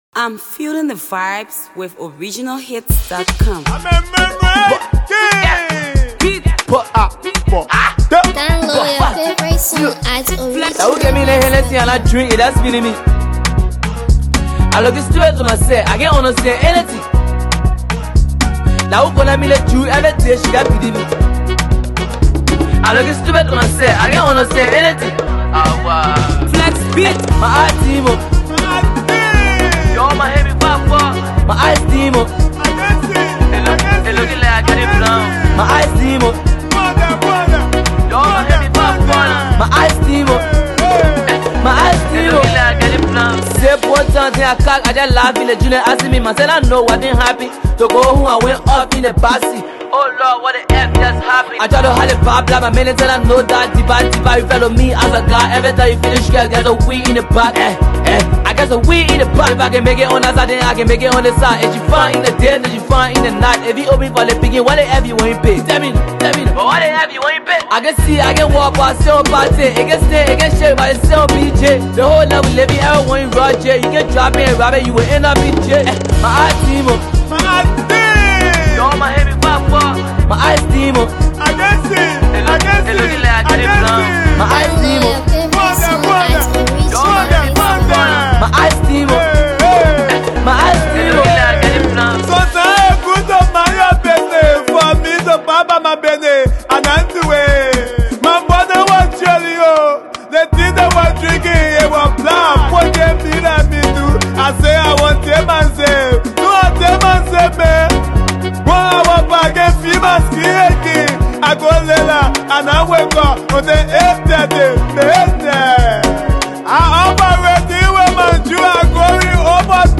AfroAfro PopMusic
Danceable xtreets banger